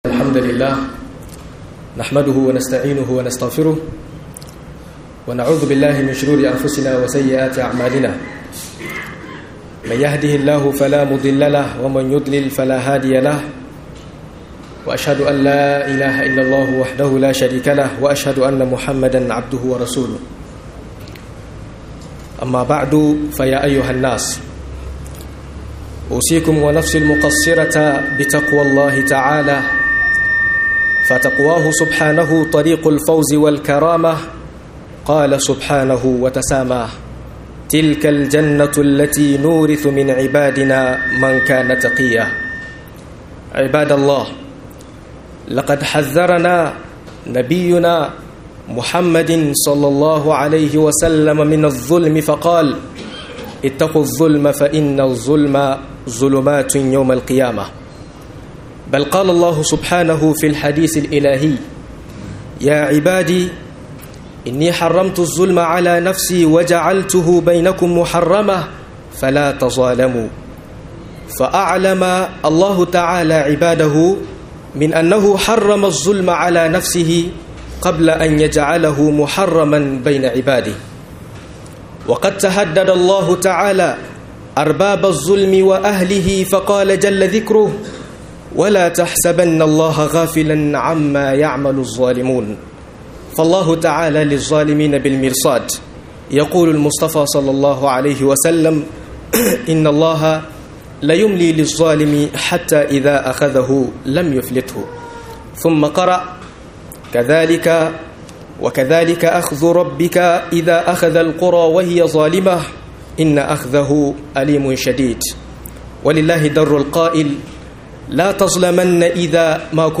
ZALUNCI DA NAW'IN SA - MUHADARA